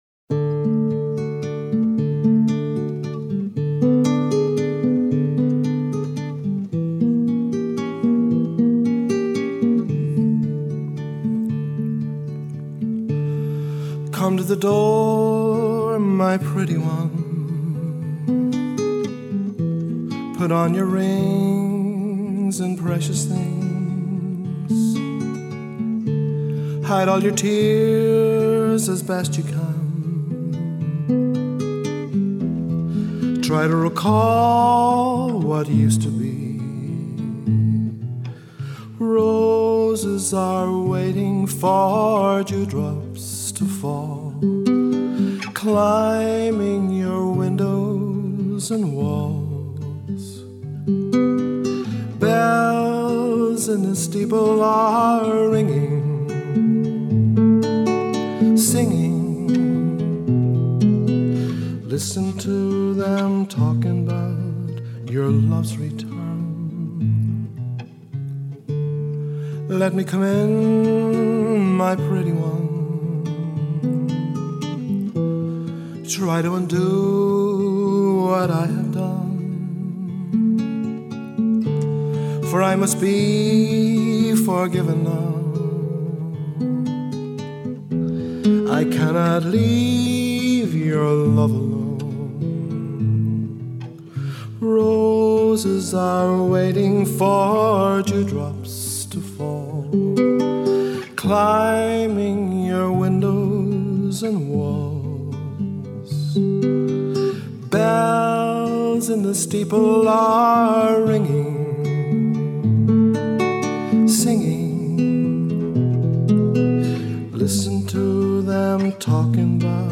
Musicians